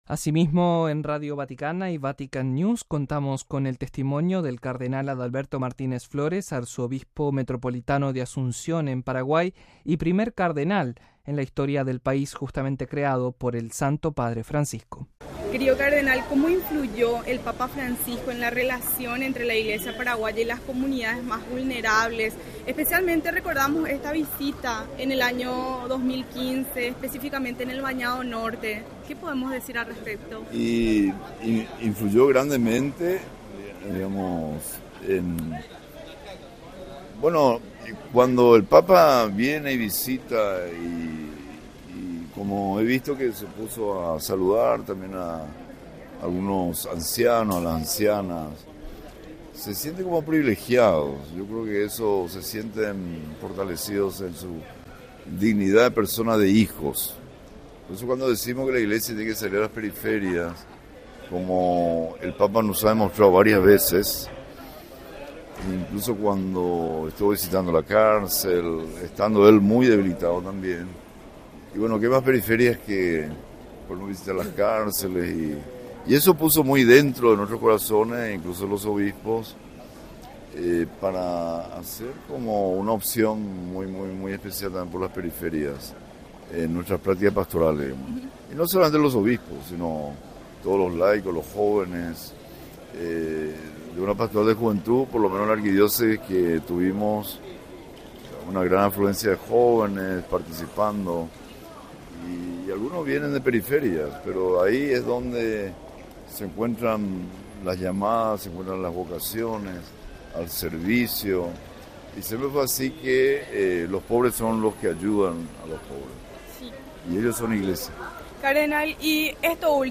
Listen to the Cardinal Adalbero Martinez Flores, President of the Association of Association, Paraguay